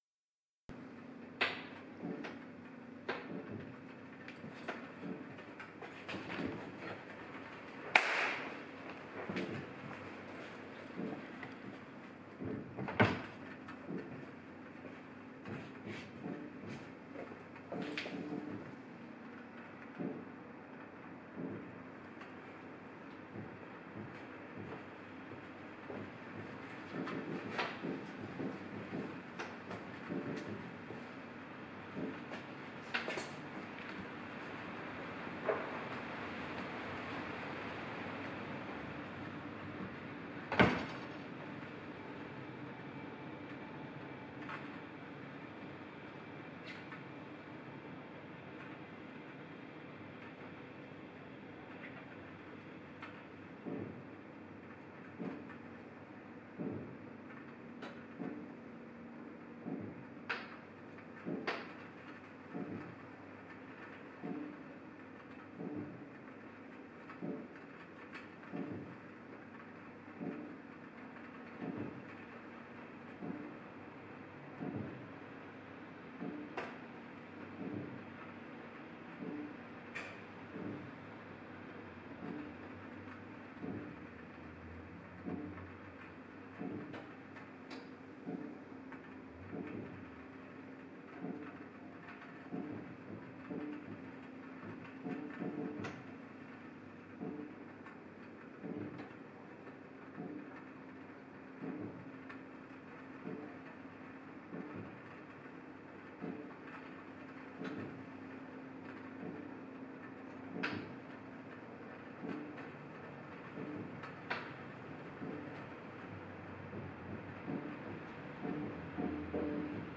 Video: aufgenommen mit: Panasonic Lumix DMC-TZ101EG-K Original Video Ton.flac (35:30) aufgenommen durch Abspielen der Video Dateien in VLC Player und Aufnehmen mit Audacity (16Bit/44,1kHz)